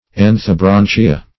Search Result for " anthobranchia" : The Collaborative International Dictionary of English v.0.48: Anthobranchia \An`tho*bran"chi*a\, n. pl.